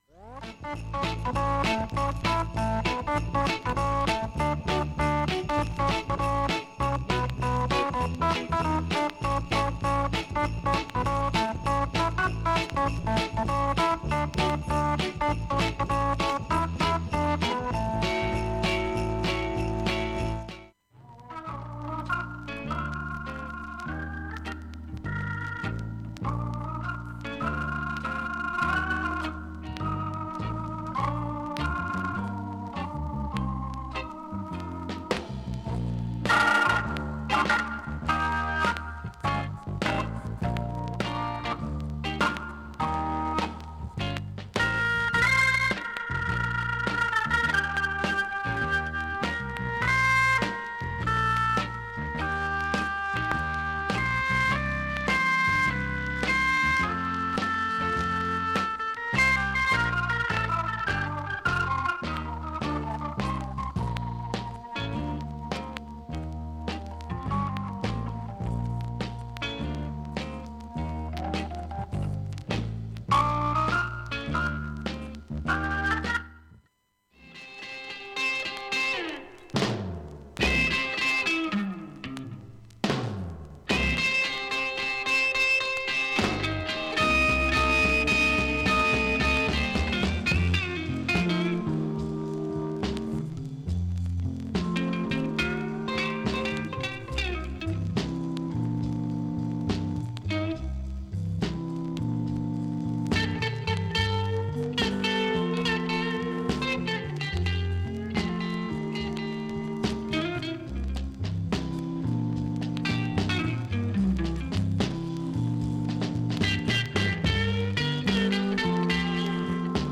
ステレオ針でも音圧良好。
現物の試聴（上記録音時間４分半）できます。音質目安にどうぞ
8回のかすかなプツが1箇所
6回のごくかすかなプツが４箇所
単発のかすかなプツが１２箇所
◆ＵＳＡ盤オリジナル Mono